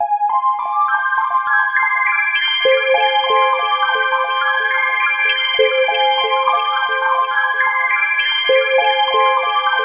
Index of /90_sSampleCDs/USB Soundscan vol.13 - Ethereal Atmosphere [AKAI] 1CD/Partition B/01-SEQ PAD B
SEQ PAD07.-R.wav